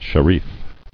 [she·rif]